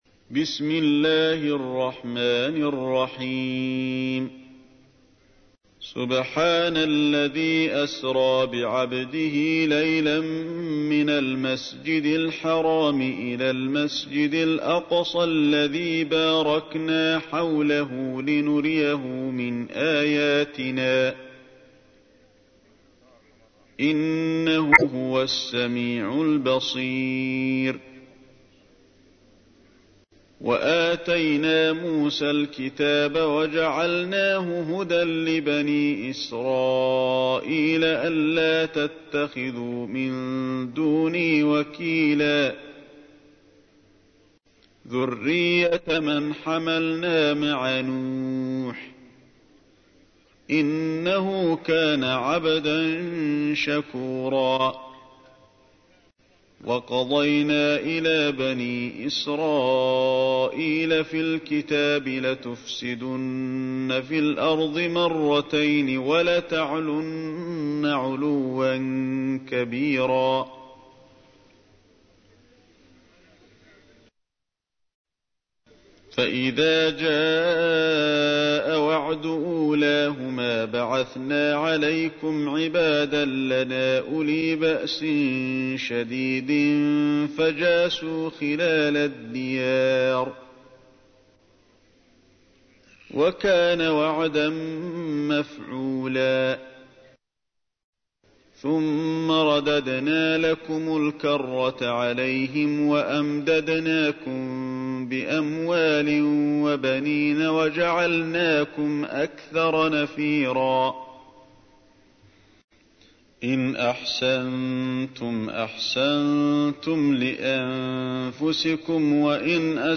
تحميل : 17. سورة الإسراء / القارئ علي الحذيفي / القرآن الكريم / موقع يا حسين